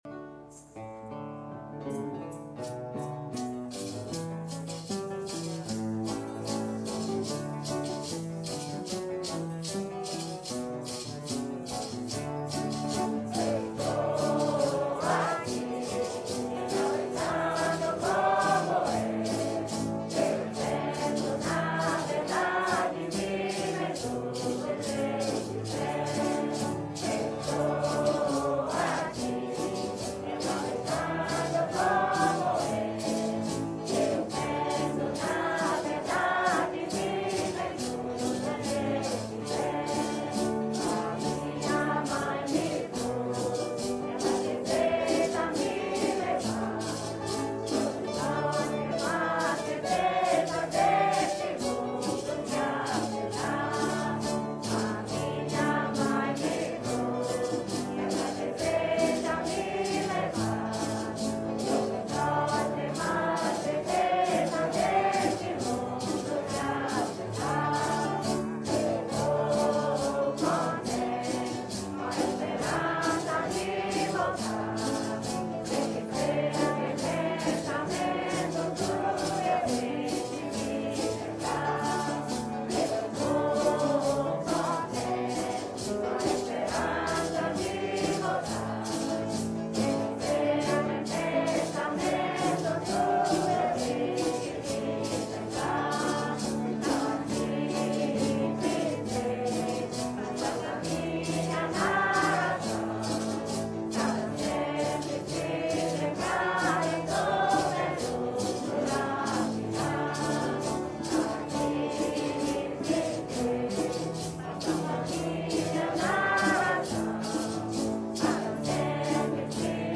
Recording source: Montreal 2008
marcha